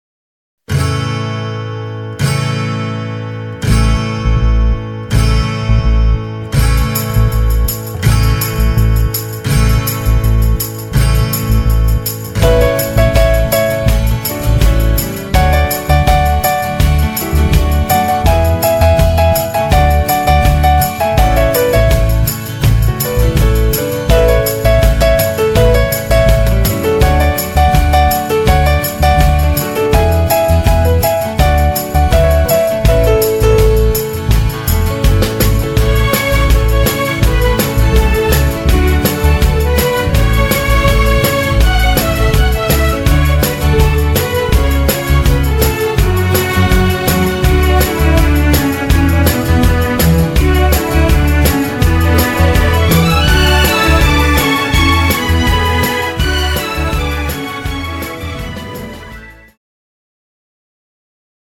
Two Step